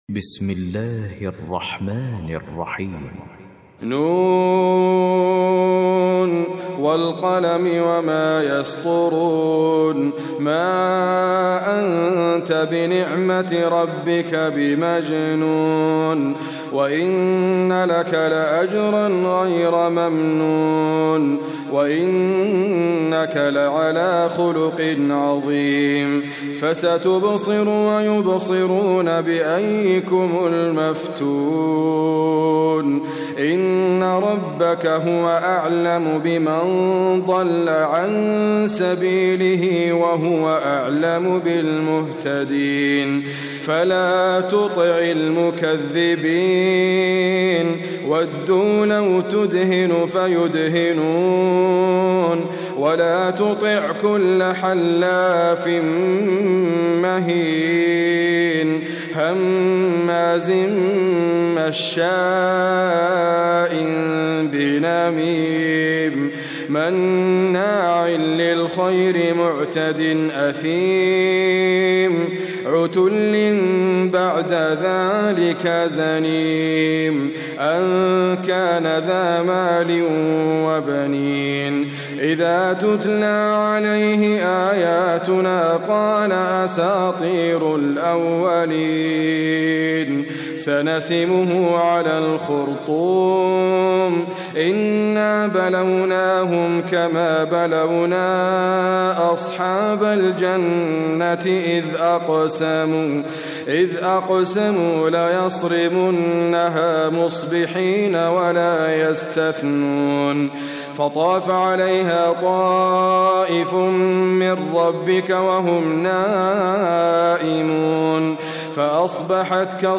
QuranicAudio is your source for high quality recitations of the Quran.